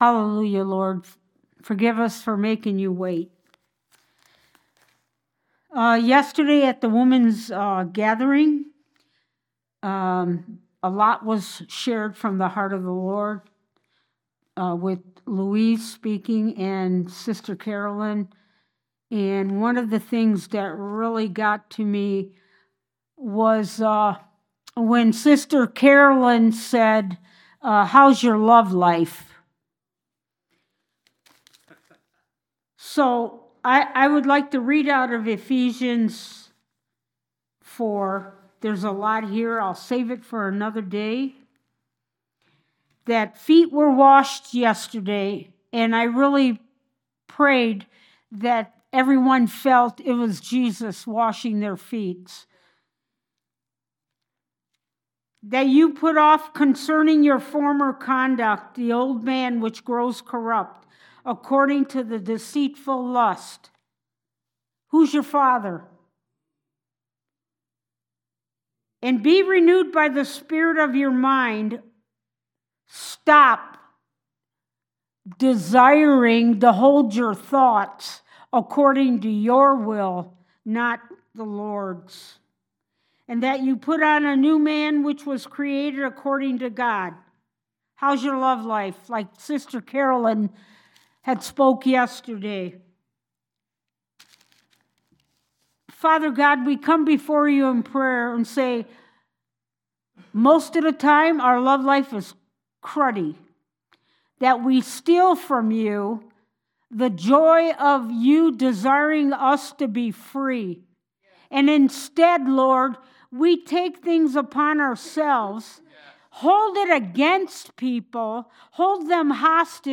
Sunday Service
Service Type: Sunday Service